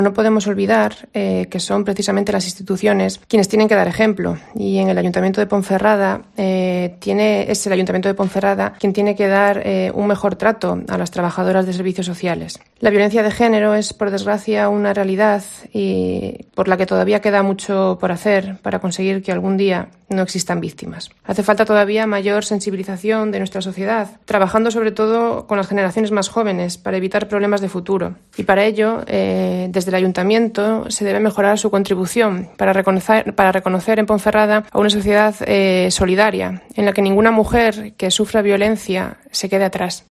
Los populares reclaman al Ayuntamiento un mejor trato para estas trabajadoras. Escucha aquí a la edil Lidia Coca